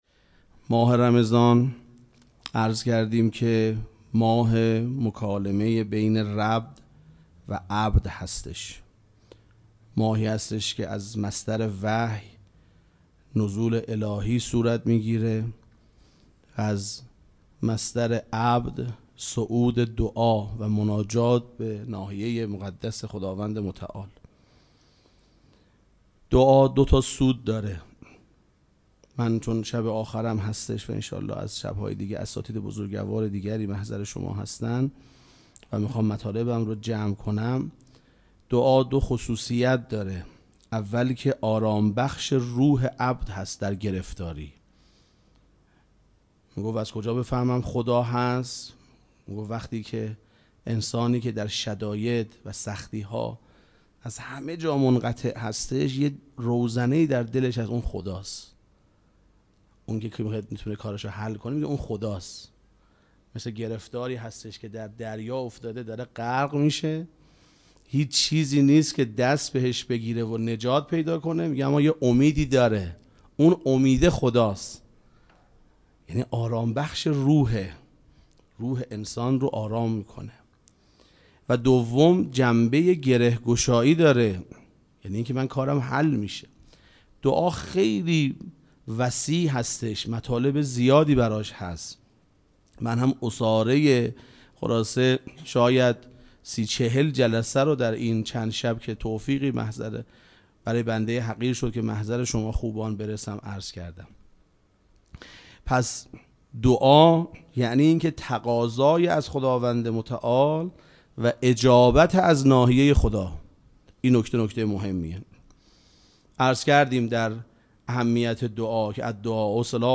سخنرانی
مراسم مناجات شب پنجم ماه مبارک رمضان چهارشنبه‌ ۱۵ اسفند ماه ۱۴۰۳ | ۴ رمضان ۱۴۴۶ ‌‌‌‌‌‌‌‌‌‌‌‌‌هیئت ریحانه الحسین سلام الله علیها